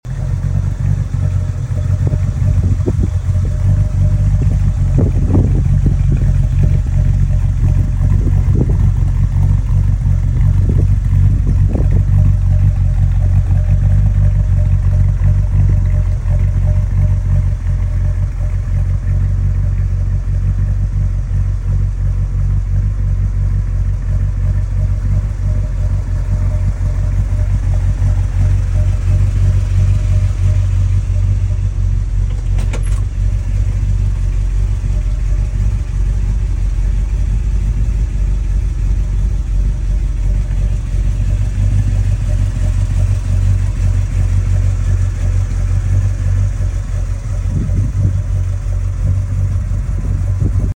Sound On 🔉 1967 Ford sound effects free download
Sound On 🔉 1967 Ford Mustang Fastback 390 GTA 🤌